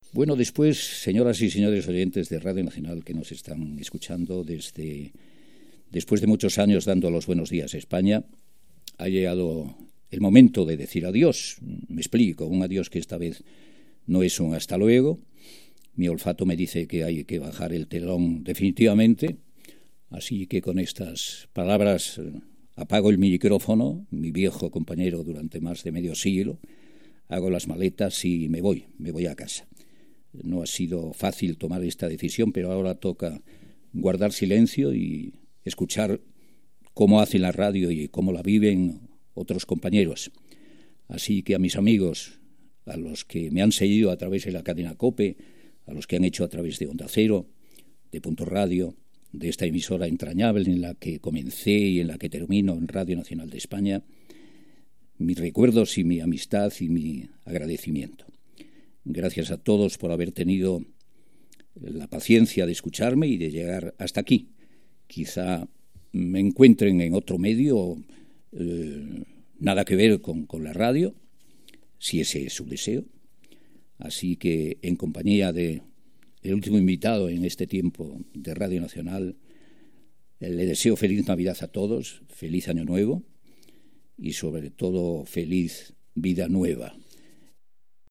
Últim espai. Luis del Olmo s'acomiada dels oients de "Protagonistas"
Informatiu